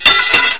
metal2.wav